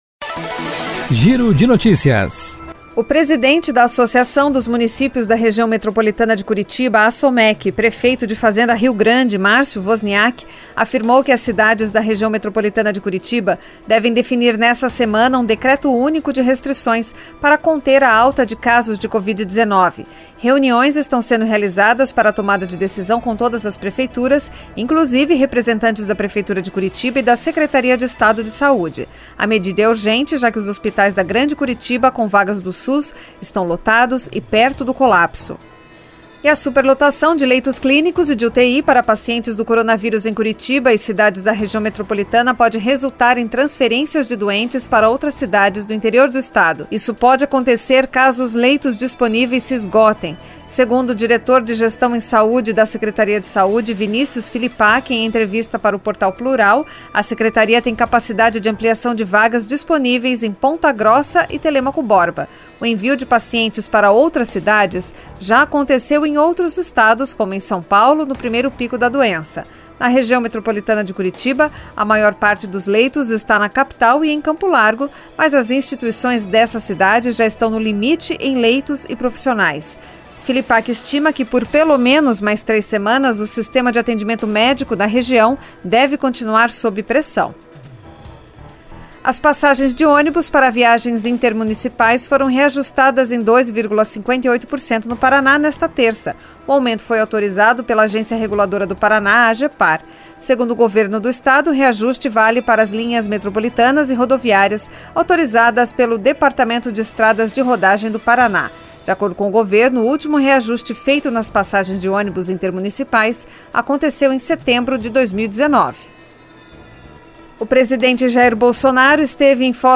Giro de Notícias Tarde COM TRILHA